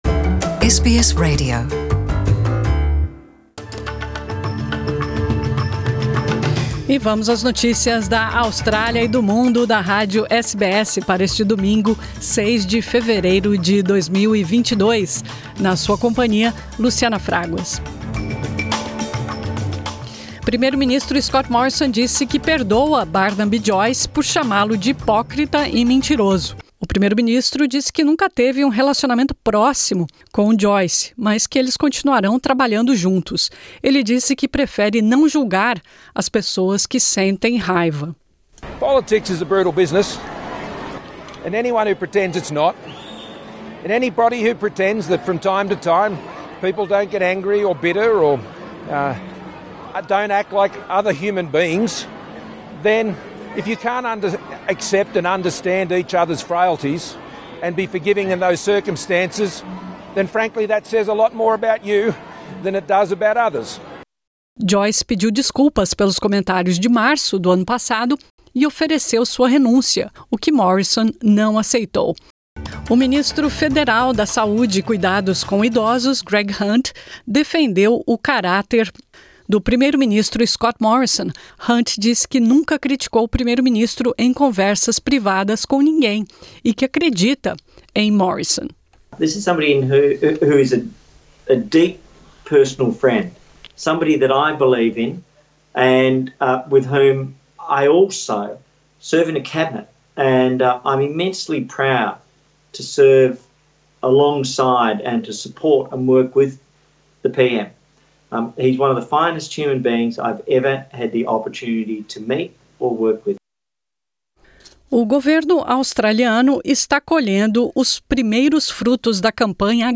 São as notícias da Austrália e do mundo da Rádio SBS para este domingo 6 de fevereiro.